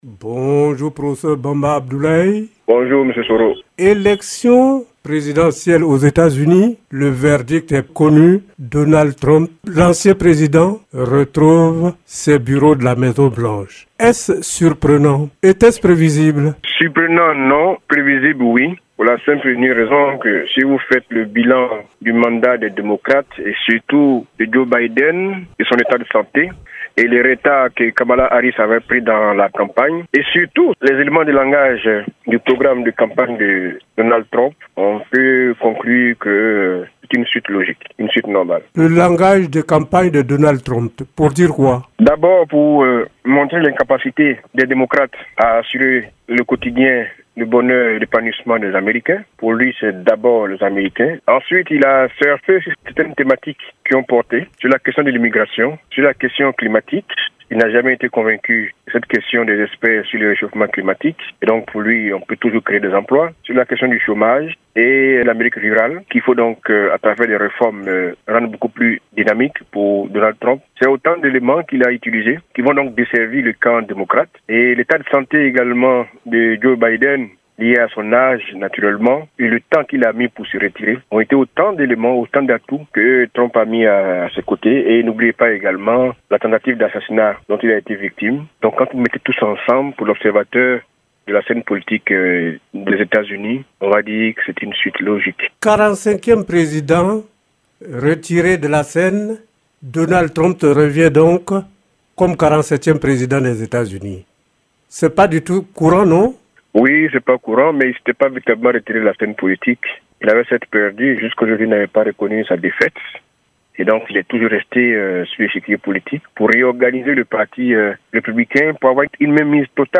L'Invité de la Rédaction